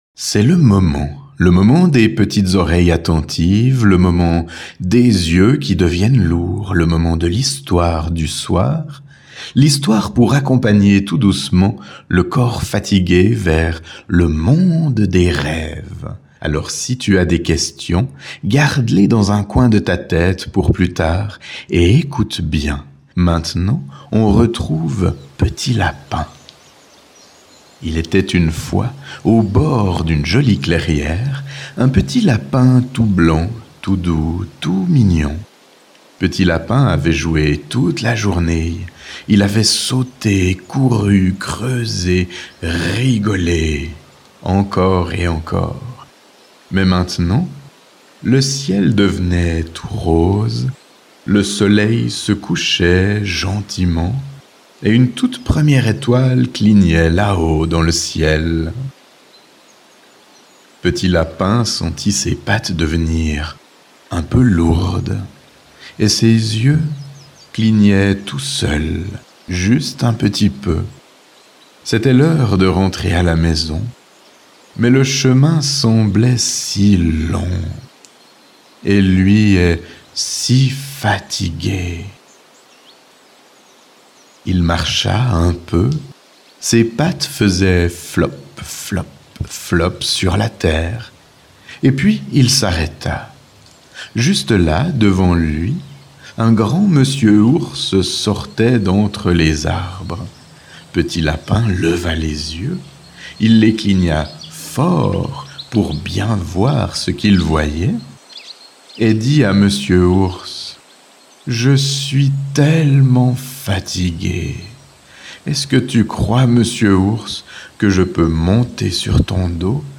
Rejoindre les enfants dans leur monde imaginaire Ces audios sont des contes métaphoriques à destination des enfants.
hypnose-enfant-petit-lapin.mp3